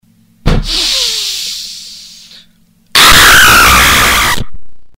Дикий крик мужчины скример